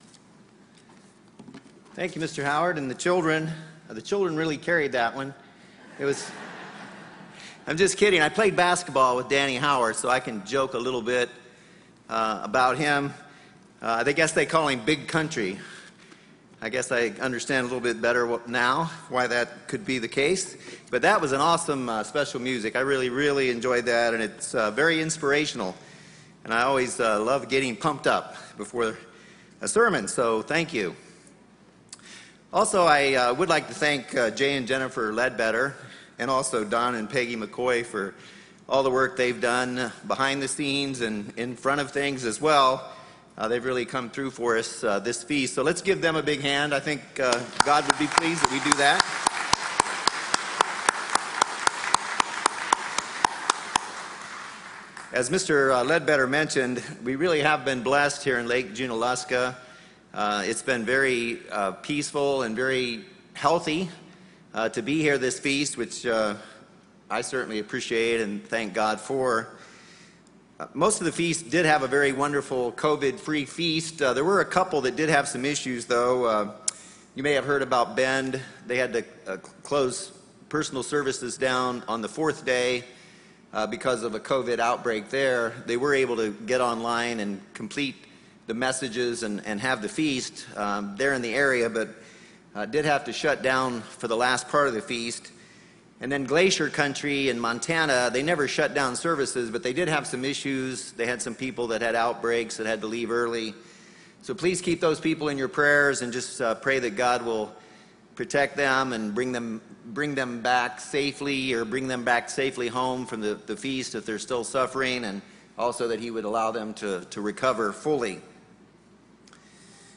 This sermon was given at the Lake Junaluska, North Carolina 2021 Feast site.